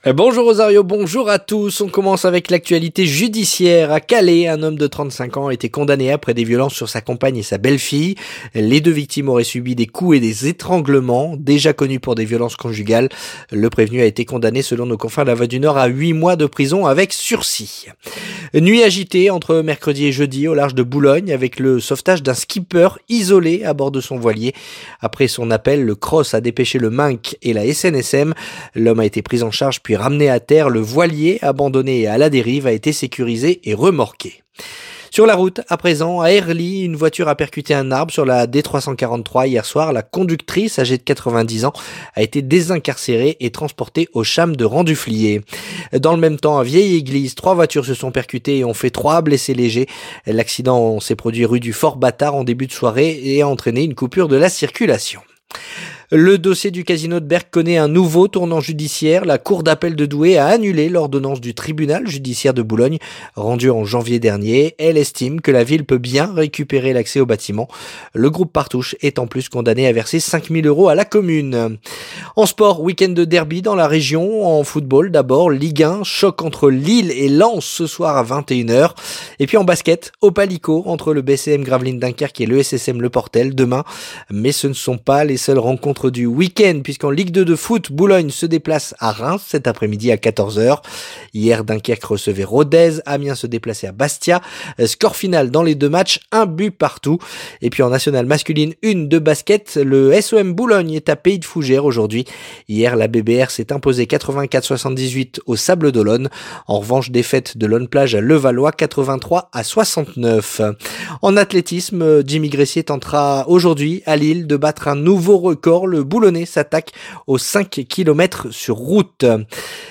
Le journal du samedi 4 avril